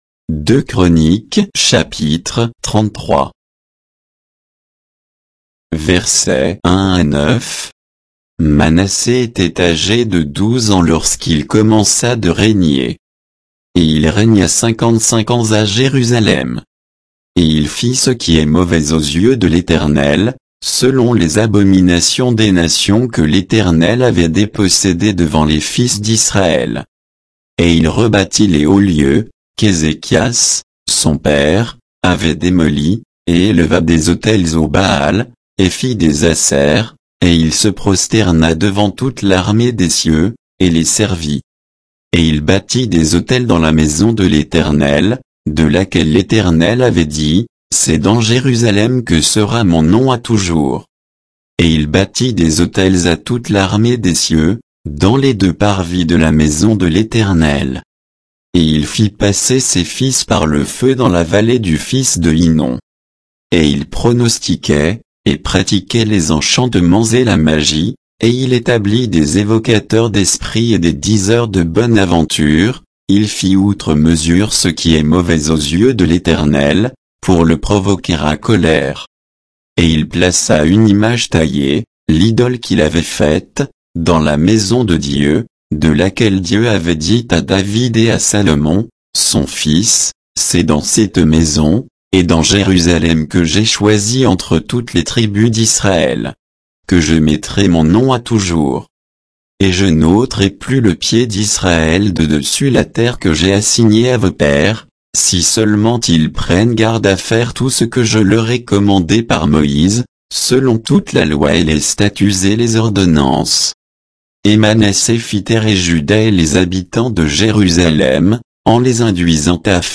Bible_2_Chroniques_33_(sans_notes,_avec_indications_de_versets).mp3